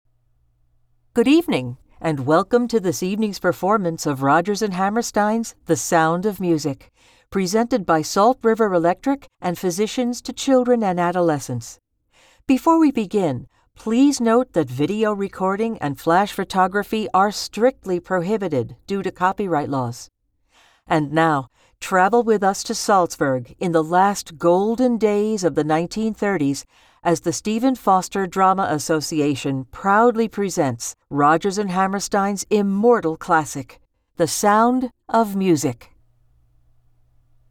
The Voice Realm represents versatile American and Canadian voice over talent with North American accents suited to international voice castings from small jobs to worldwide campaigns.